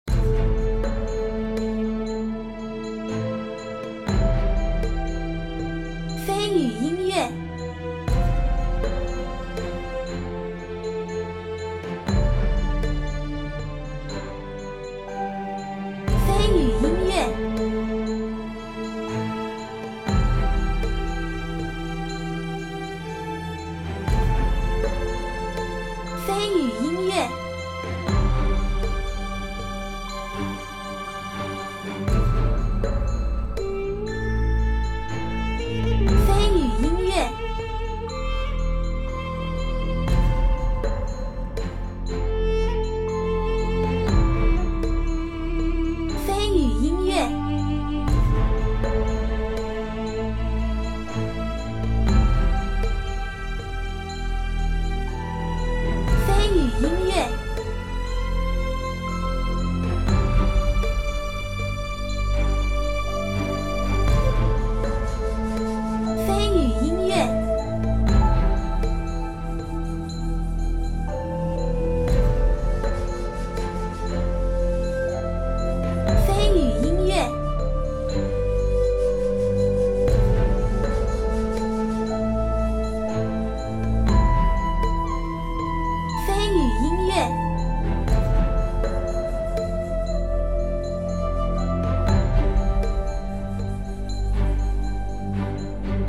正常对局BGM 管弦 氛围